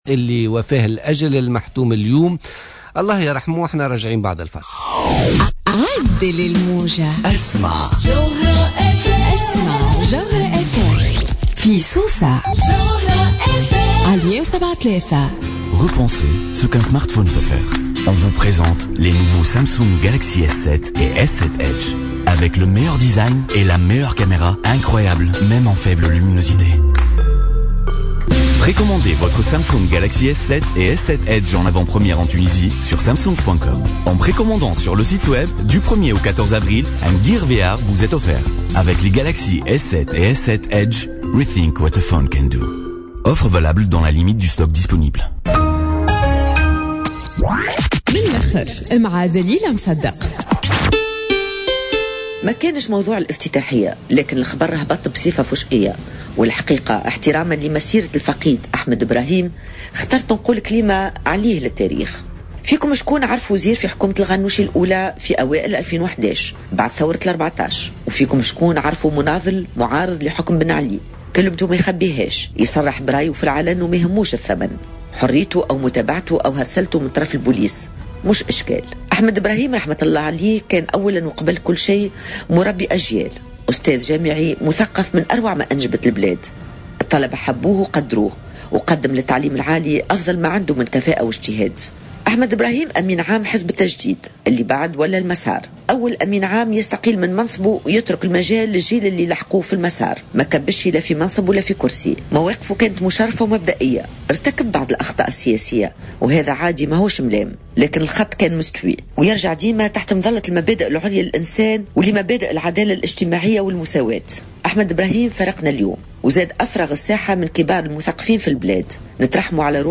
Dans une intervention sur les ondes de Jawhara FM ce jeudi 14 avril 2016, dans le cadre de l’émission Politica, le secrétaire général du parti Al Massar, Samir Ettaieb a indiqué que le défunt Ahmed Brahim était le seul député à avoir renoncé à sa pension de retraite.